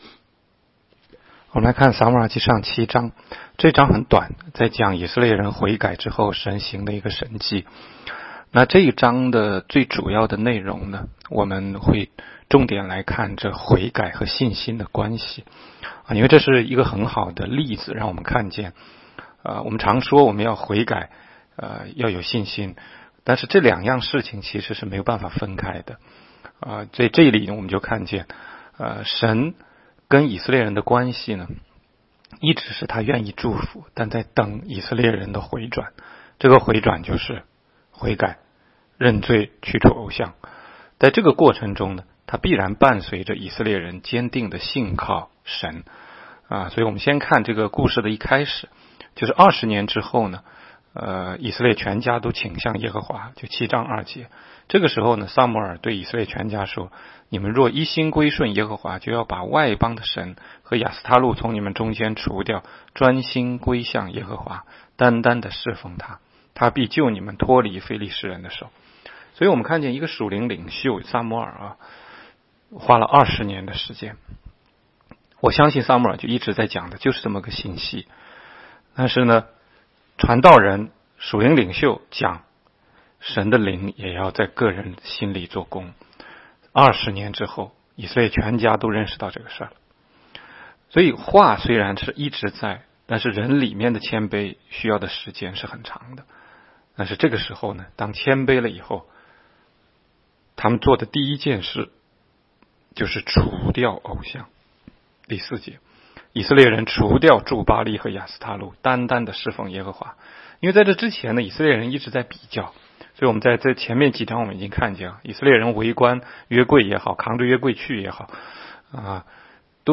16街讲道录音 - 每日读经-《撒母耳记上》7章